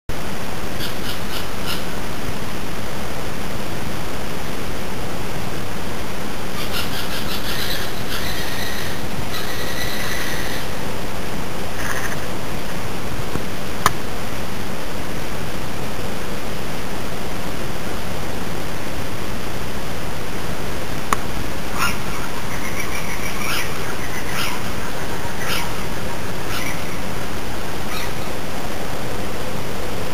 Ho fatto una piccola registrazione durante la notte...con una piccola penna..insomma l'audio è pessimo ed il volume è bassissimo.
L'uccello era molto molto vicino e richiamava un altro uccello molto più lontano, si alternavano nel canto.
le registrazioni si sento abbastanza bene, hanno però molti rumori di sottofondo che danno fastidio.
In effetti, riascoltando, ora penso che sia molto probabile che siano Civette, per la presenza di brevi richiami "puntuti" ("tuìiu")… ma, appunto, sentiamo gli altri.